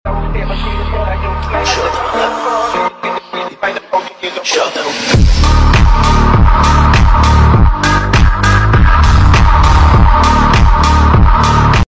Bmw F80 Sound: Demons in sound effects free download